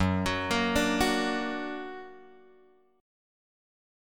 F#+ chord {2 1 4 3 3 x} chord